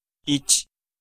Ääntäminen
IPA: /i.tiꜜ/